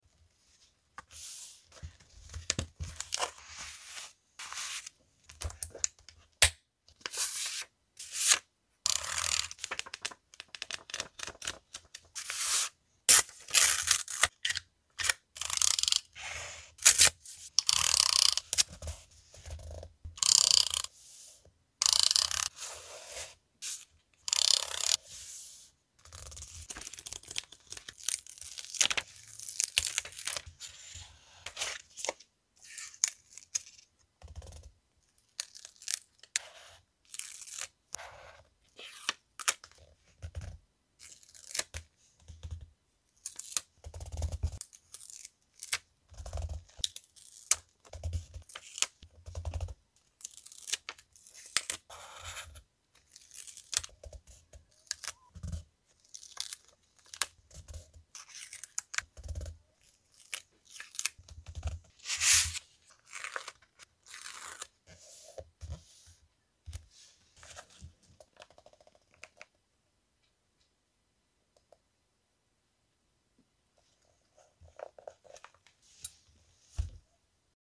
ASMR | Autumn/Fall junk journal sound effects free download
no voice - relaxing content